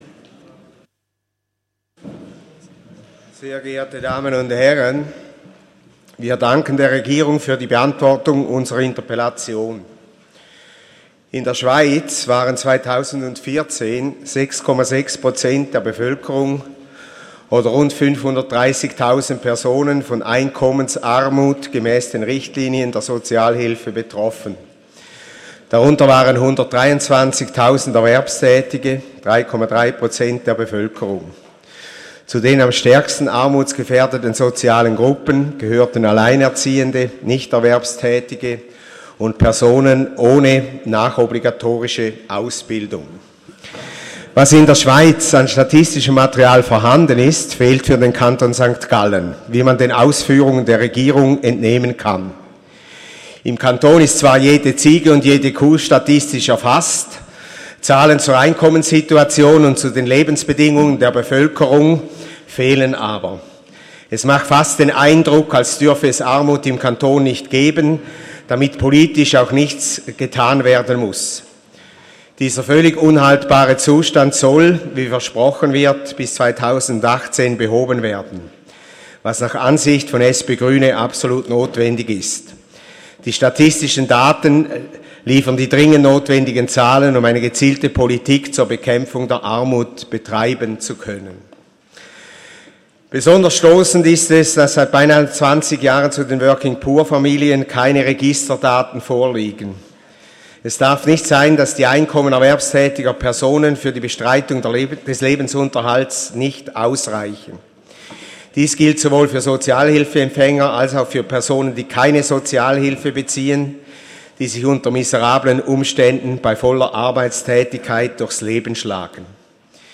12.6.2017Wortmeldung
Session des Kantonsrates vom 12. und 13. Juni 2017